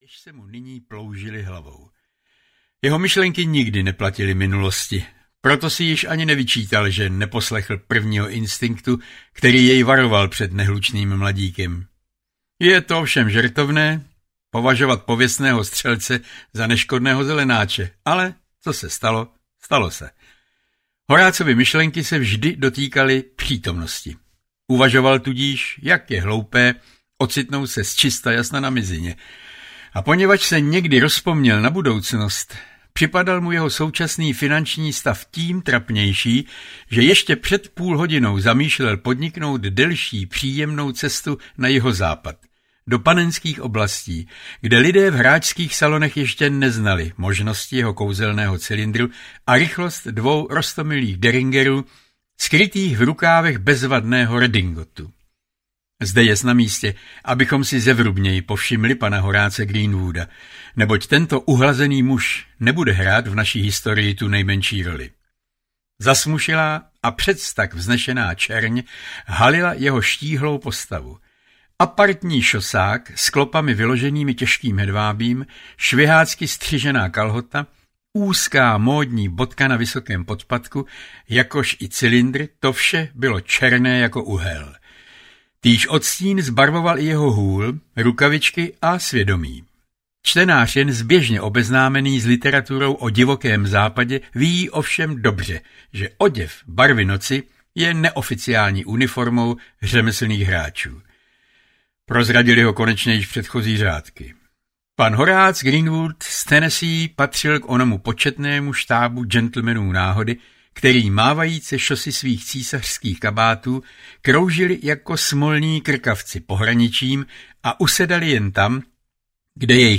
Limonádový Joe audiokniha
Ukázka z knihy